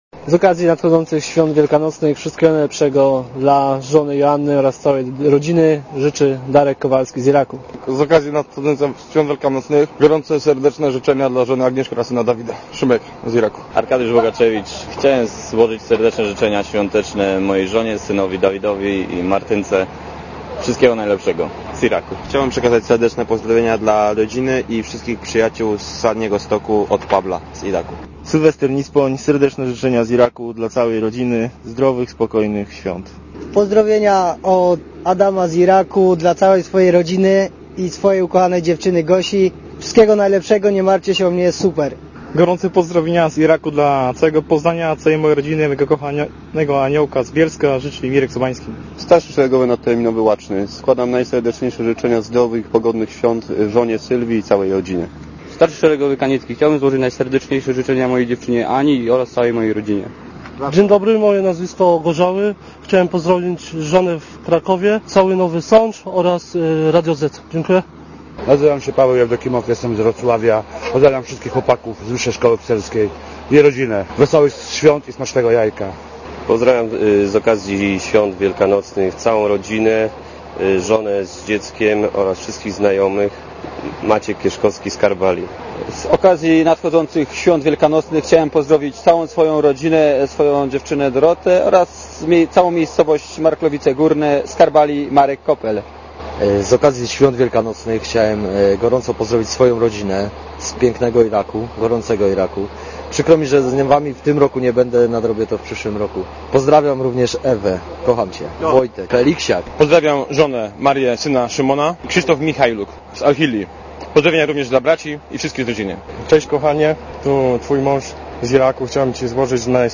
Posłuchaj życzeń od polskich żołnierzy w Iraku